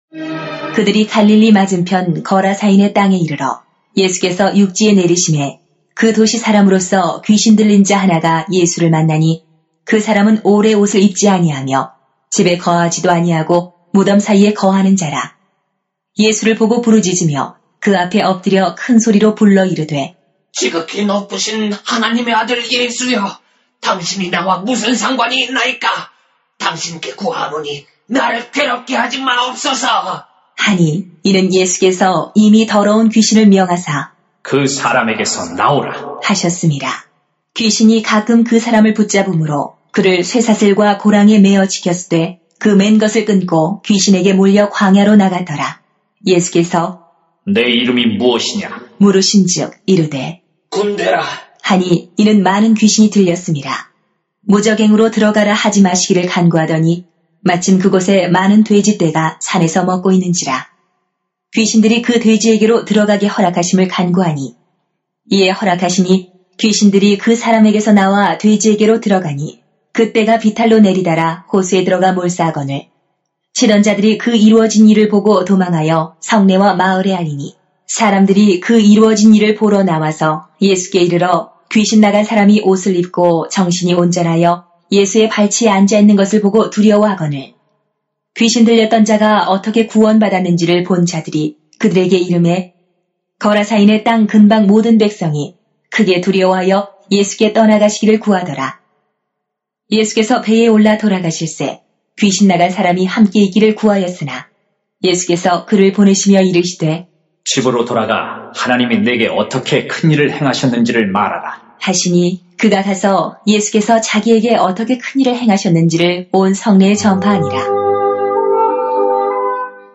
[눅 8:26-39] 귀신들린 사람이 예수님의 제자가 되다 > 새벽기도회 | 전주제자교회